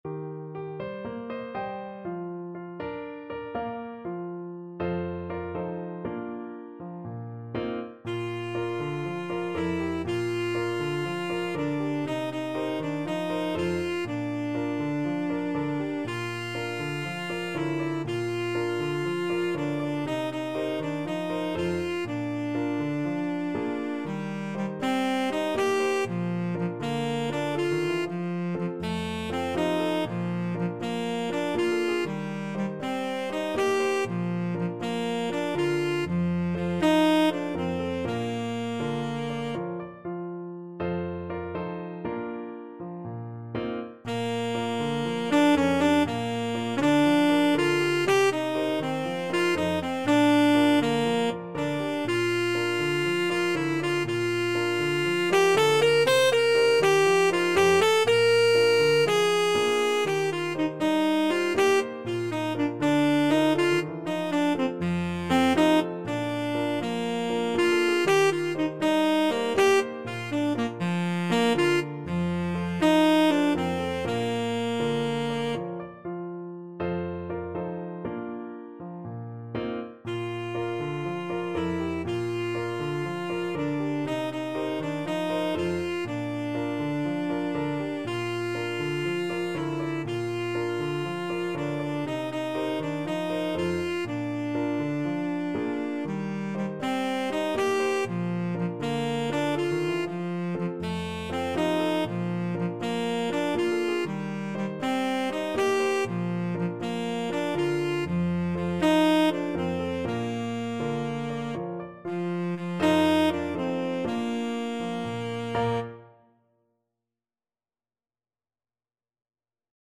4/4 (View more 4/4 Music)
Calypso = 120